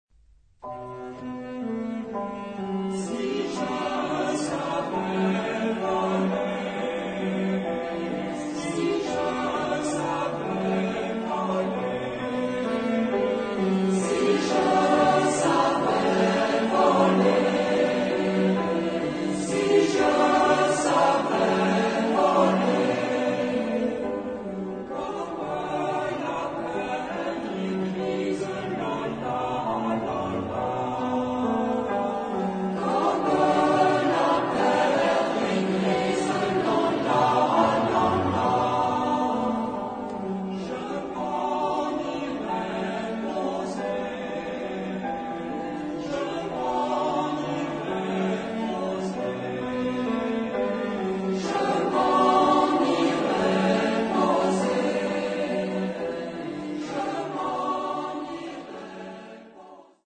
Genre-Style-Forme : Profane ; Populaire
Caractère de la pièce : modéré
Type de choeur : FH  (2 voix mixtes )
Tonalité : si mode de la
Origine : Guyenne